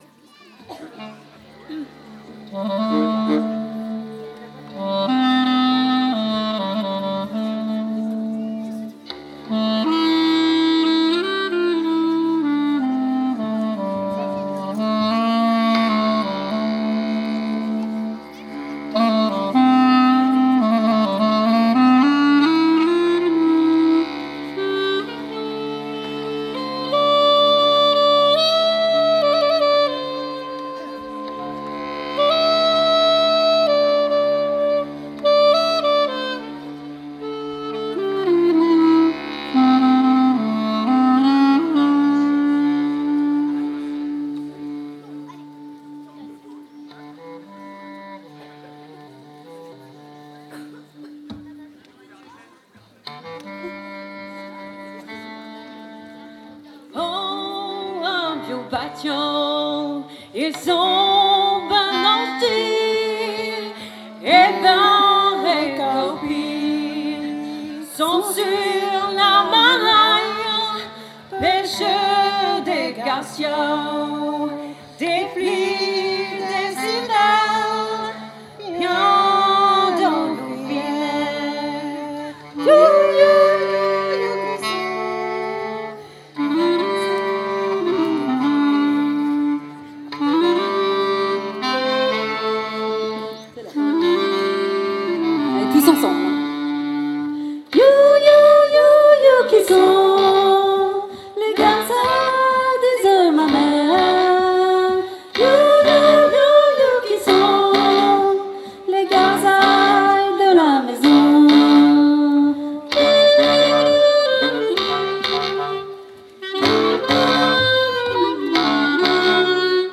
04_you_qi_sont-chant-clarinette.mp3